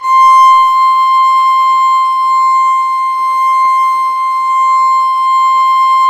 Index of /90_sSampleCDs/Roland - String Master Series/STR_Violin 4 nv/STR_Vln4 _ marc